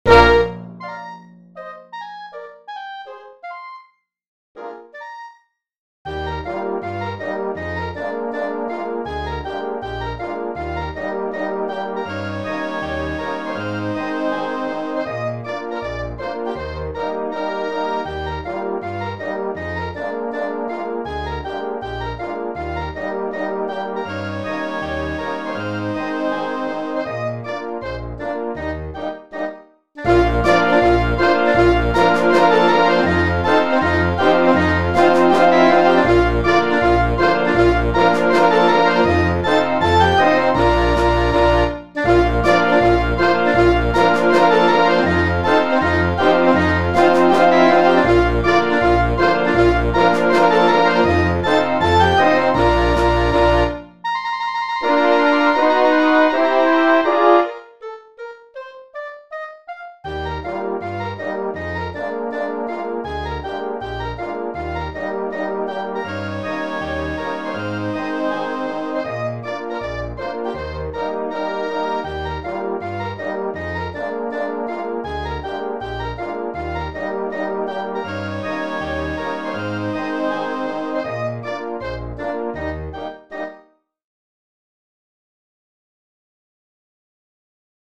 für großes Blasorchester…